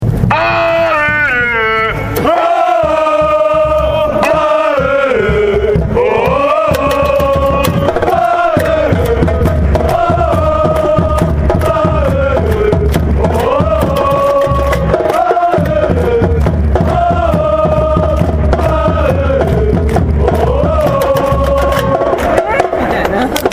ここではヴェルディのゴール裏で歌われている応援歌を載せています。
より高音質でヴェルディの応援歌をお届けするために録音機材を変更しました。
が付いているものは新機材でステレオ収録したハイクオリティサウンドです。
ヘッドホンを付けて聞くとかなりの臨場感がありますので是非お試しください！！
「*」の部分は太鼓・手拍子を表しています。
♪選手ソング♪